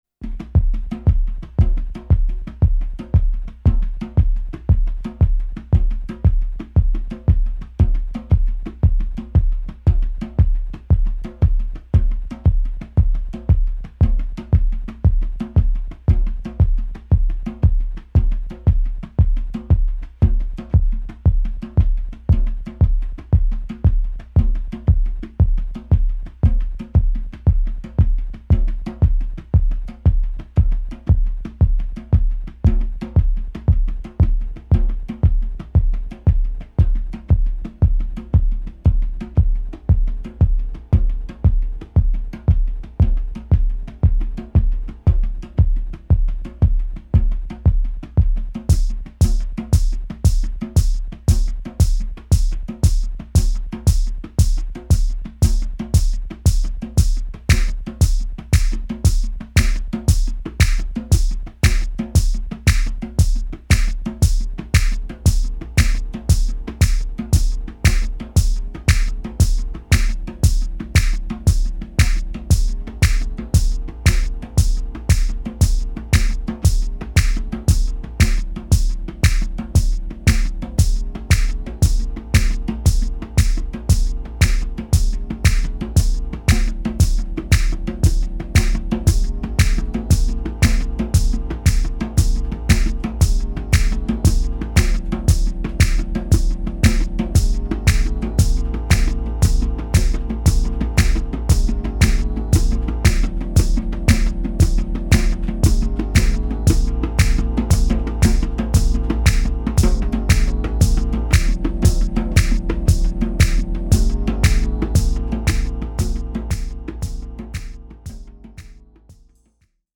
here we have 4 tracks of classic Deep House from Detroit.